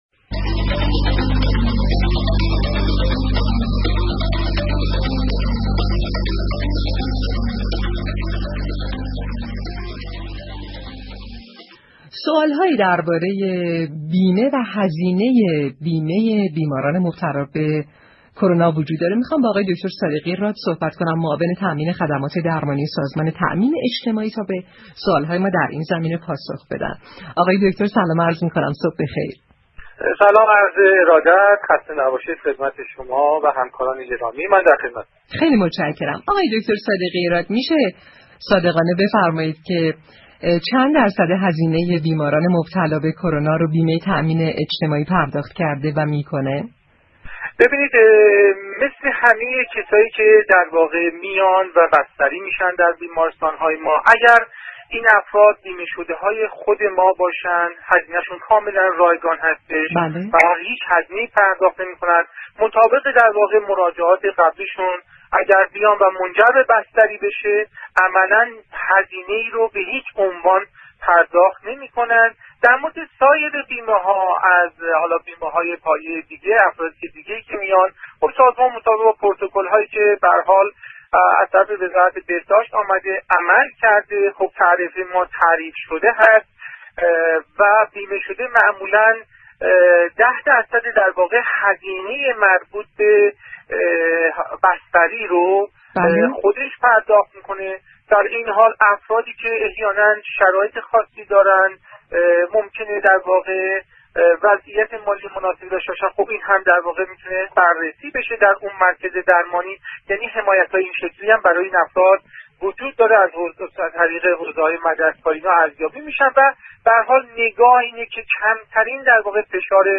دكتر سیدمجید صادقی راد، معاون تامین خدمات درمانی سازمان تامین اجتماعی در گفتگوی تلفنی با برنامه تهران كلینیك رادیو تهران ، آخرین تسهیلات این سازمان برای بیماران كرونایی را تشریح كرد.